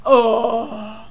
technician_die2.wav